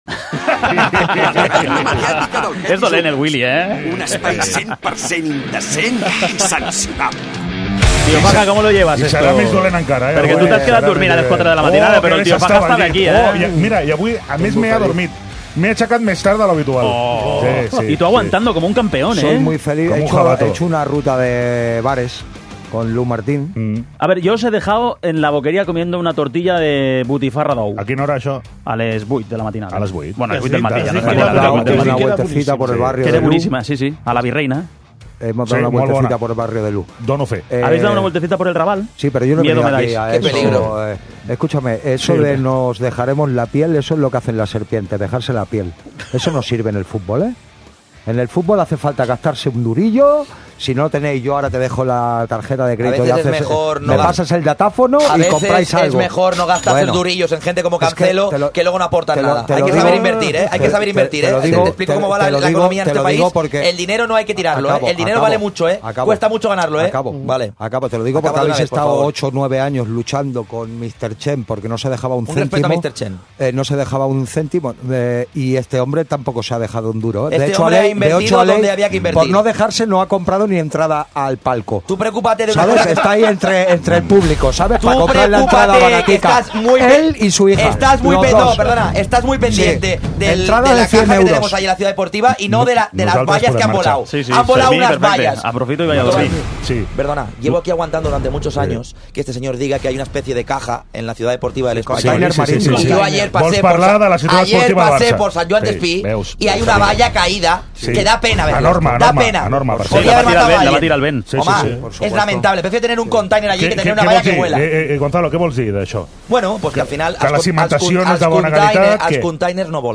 Escolta la tertúlia més irreverent de la ràdio